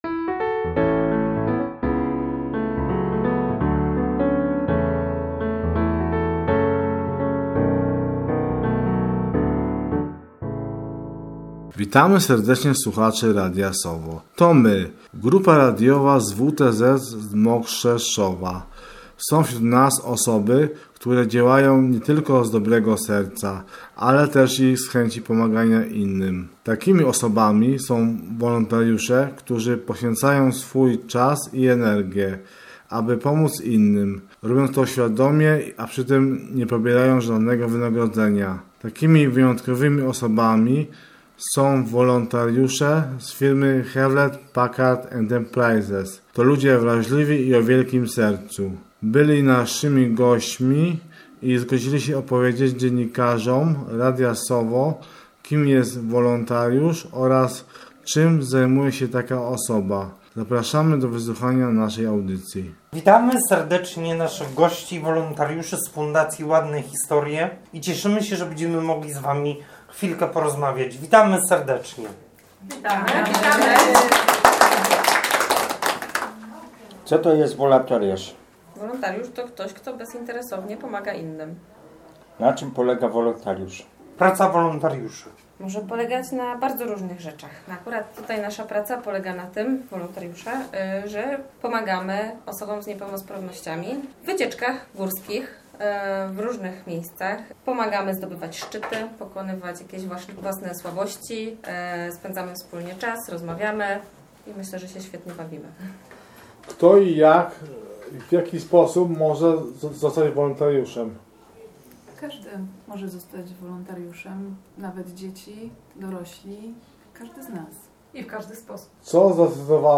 O tym co dla nich znaczy wolontariat, czym jest wspieranie osób z niepełnosprawnościami opowiedzieli nam sami wolontariusze, w krótkim wywiadzie nagranym podczas wizyty w Warsztacie Terapii Zajęciowej w Mokrzeszowie.